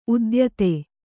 ♪ udyate